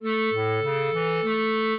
minuet7-8.wav